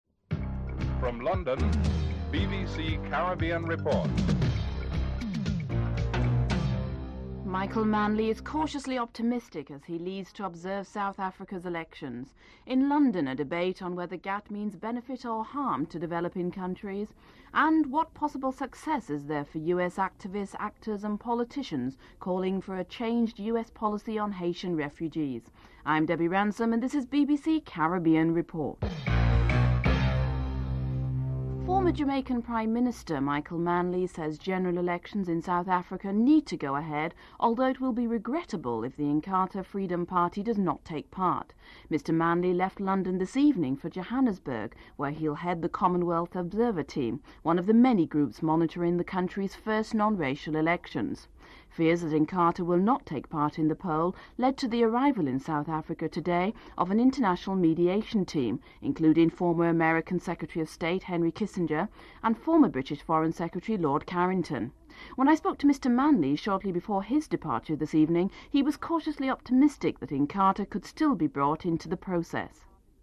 The British Broadcasting Corporation
7. Wrap up & theme music (14:40-14:57)